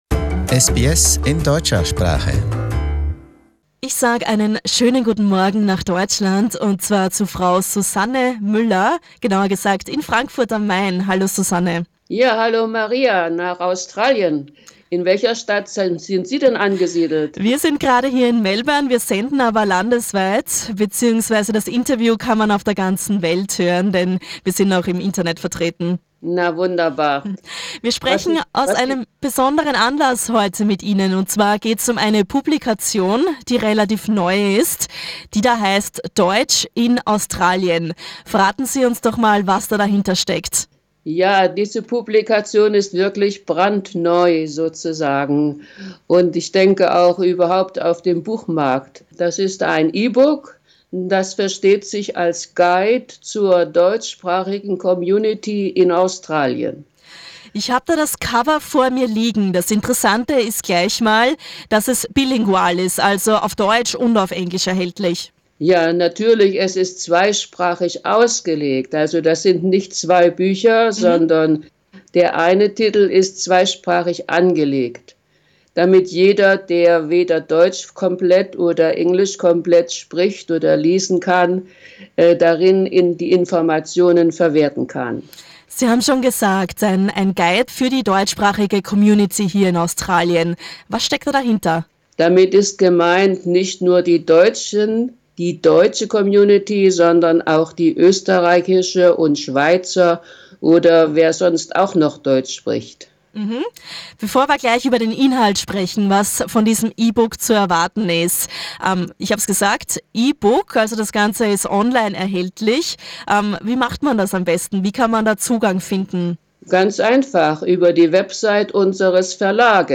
Skype-Interview